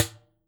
Dustbin3.wav